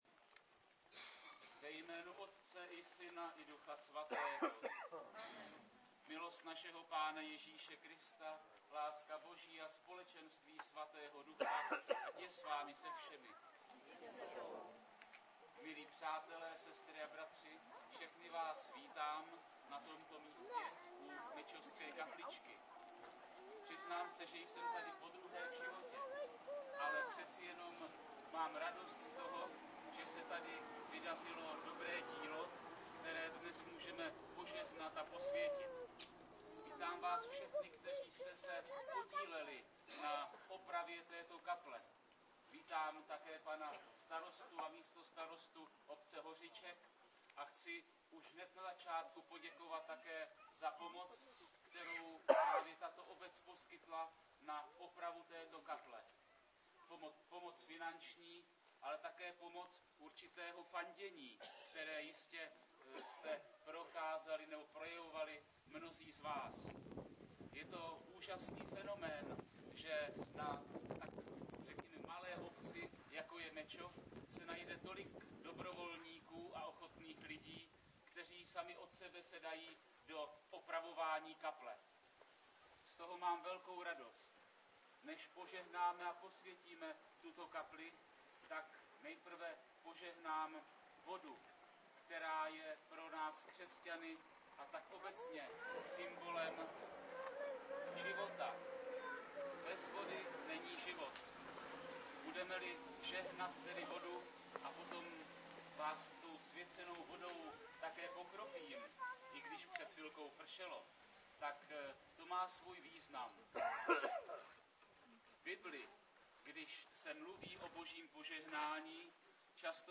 Požehnání kapličky
promluva-pana-farare.mp3